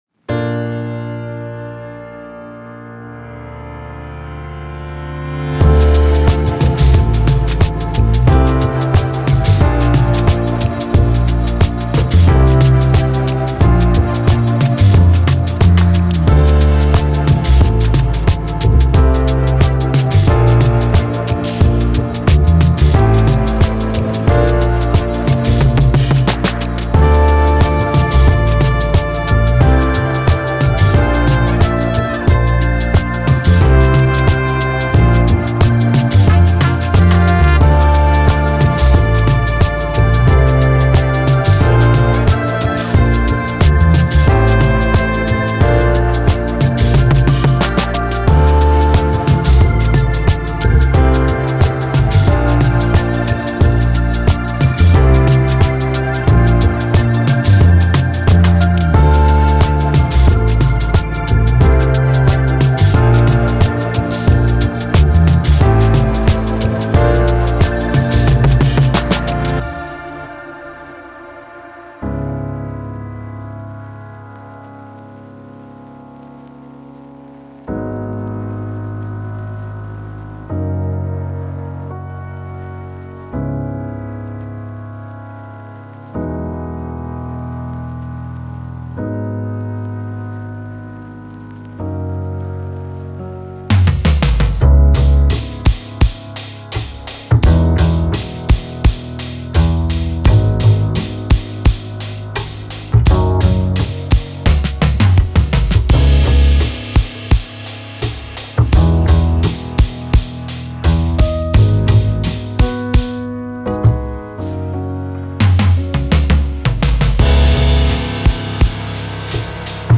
Genre:Soul
実際の楽器を音楽的で最小限の信号経路で録音しました。
ベースとギターにはDIをマイク録音したチューブコンボアンプの1x12キャビネットとブレンドしました。
セッションは木製フロアのある処理済みライブルームで行われ、必要に応じてアンプ用のアイソレーションブースも使用しました。
タイミングは意図的に人間味を保ち、ソウルやブーンバップ特有の微妙なスウィングやビートの裏拍フレーズを取り入れています。
ソウル、ファンク、ヒップホップ、ブーンバップ、ローファイ、R&B
73 - 154 BPM